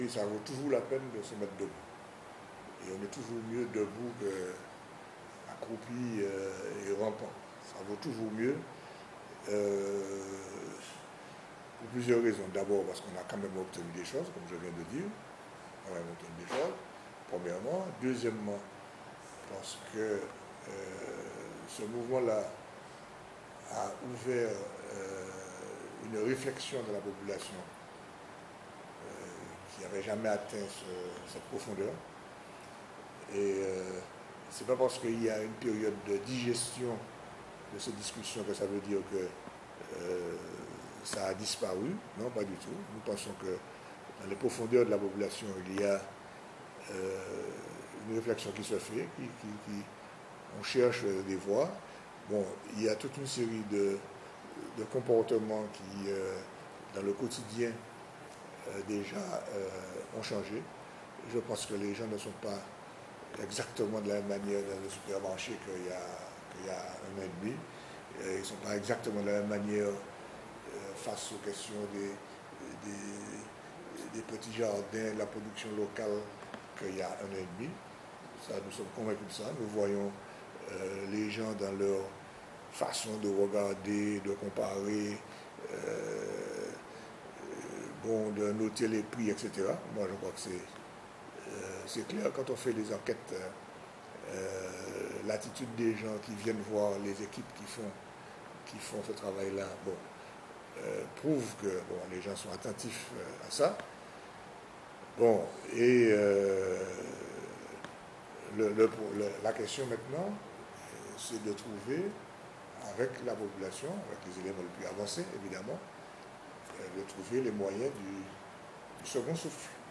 Interview à lire et à écouter.